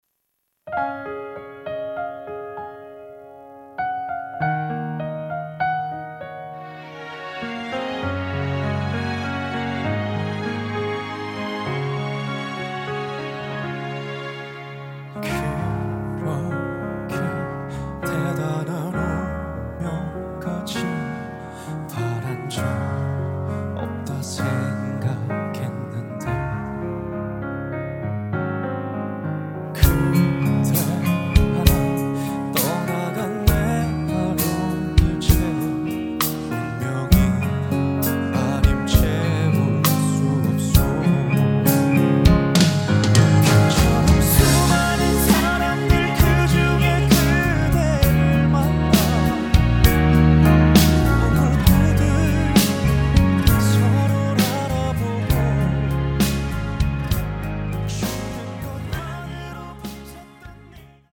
음정 원키 3:44
장르 가요 구분 Voice MR